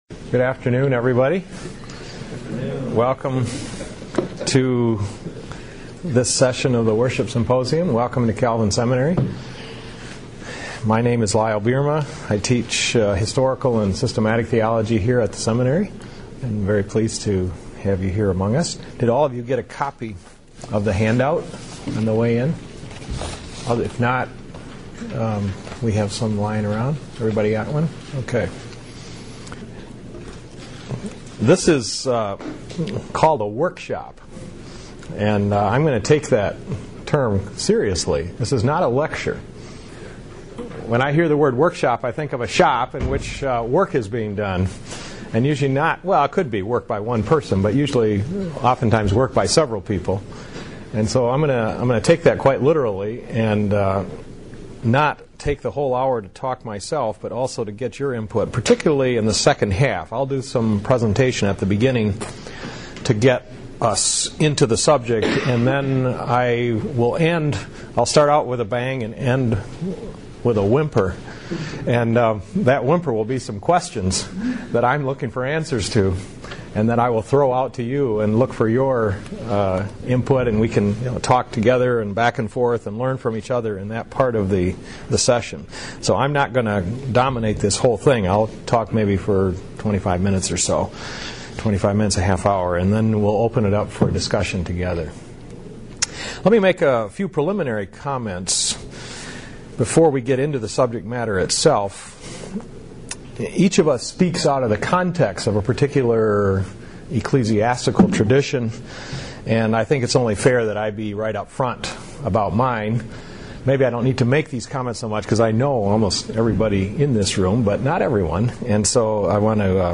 Presented at the 2006 Calvin Symposium on Worship.